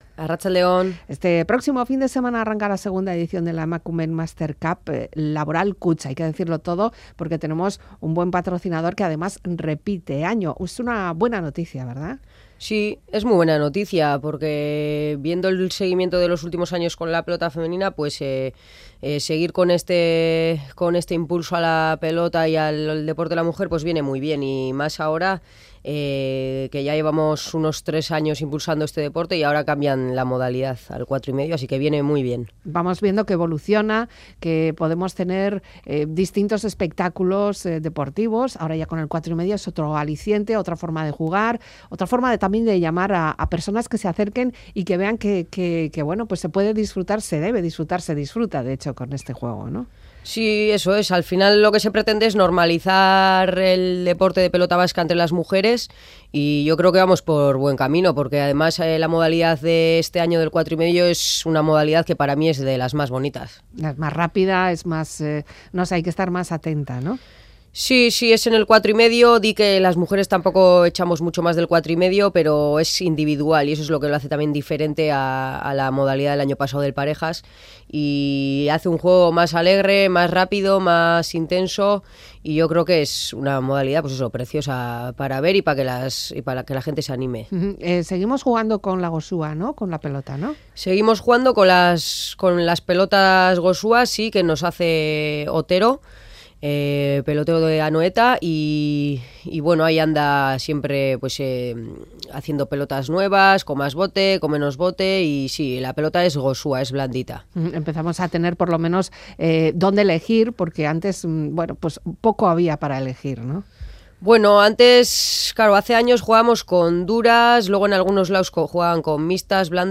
Conversación